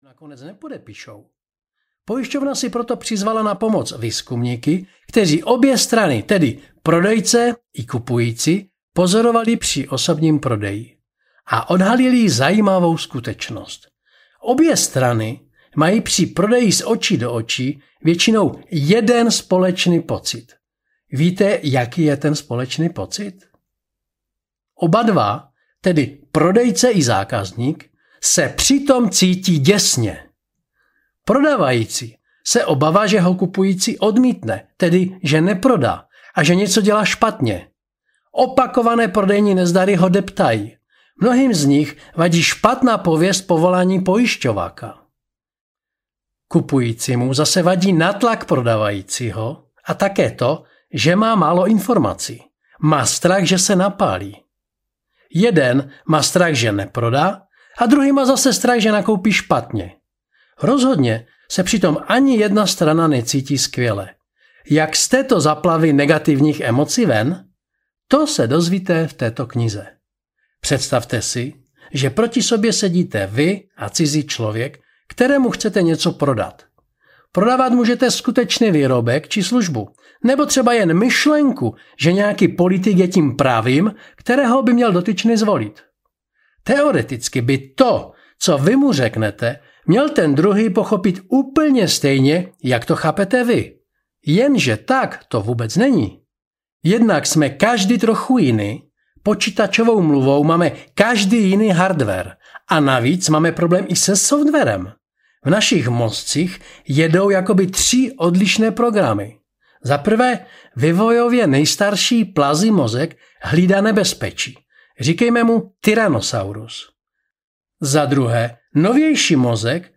Jak prodávat z očí do očí audiokniha
Ukázka z knihy